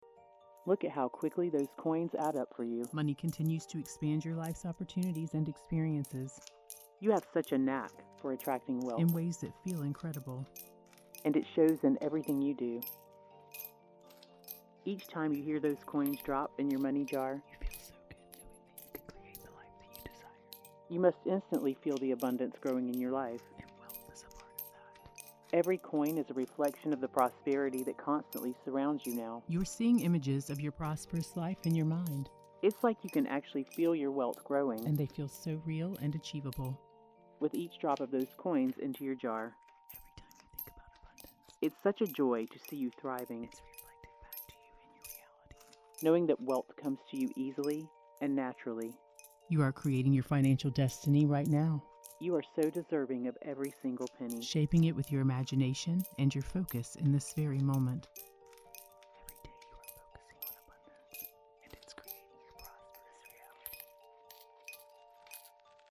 Created and produced to surround you with affirmations and sound effects, this 8-hour extended version combines powerful suggestions and sensory-rich layers that guide you into the frequency of money.
Raining Money: Feel the gentle shower of wealth flowing into your life as you hear the sound of money falling around you.
Counting Coins in a Jar: Hear the satisfying clink of coins, each representing growing wealth and opportunities.
Popping Champagne & Clinking Glasses: Celebrate your success with the sound of champagne bottles popping and glasses clinking in victory.
3D Spatial Audio Experience: This session uses 3D spatial sound to place affirmations and sound effects around your head, creating a fully immersive auditory environment.
The affirmations come from all directions, creating a multi-dimensional effect that amplifies the suggestions.
Powerful Storytelling: Each section begins with a narrative paragraph that tells the story of how easily you’re manifesting wealth, setting the stage for the affirmations to sink deeper into your subconscious mind.